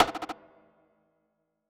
Snares / Sun Snare